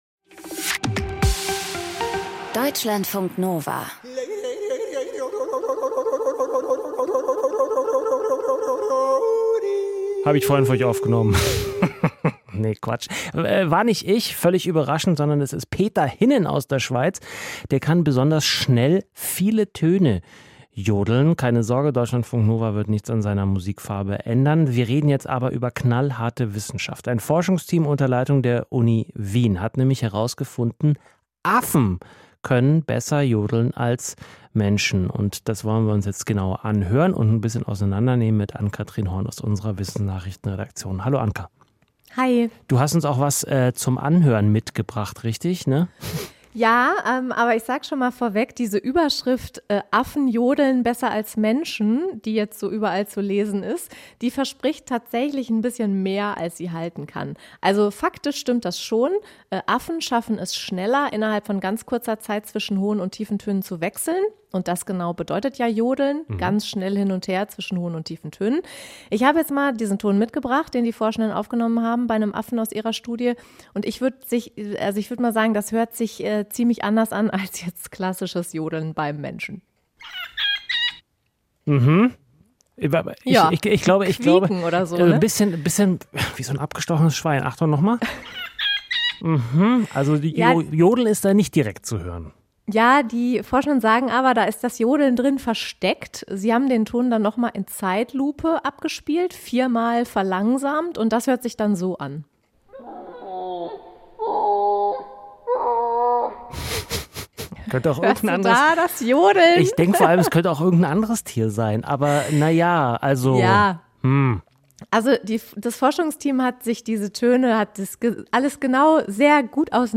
Aus dem Podcast Studio 9 Podcast abonnieren Podcast hören Podcast Studio 9 Der Überblick mit Hintergrund: Interviews, Reportagen, Kritiken – mit originellen...